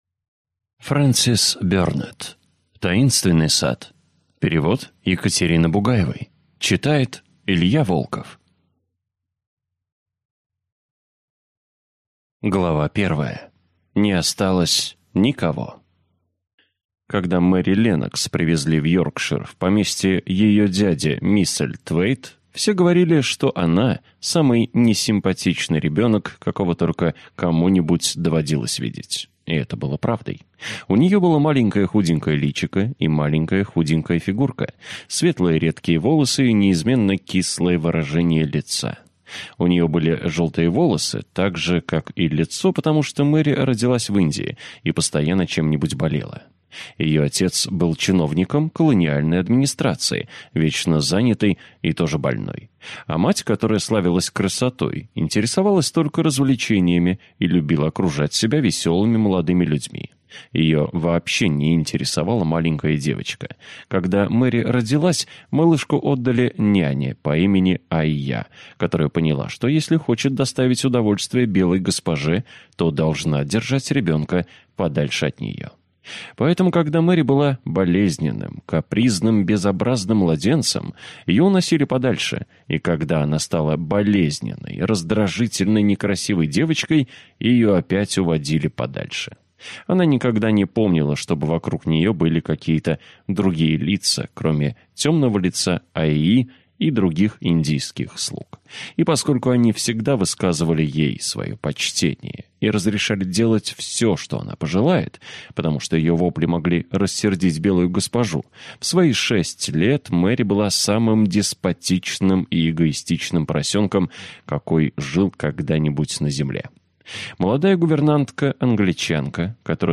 Аудиокнига Таинственный сад - купить, скачать и слушать онлайн | КнигоПоиск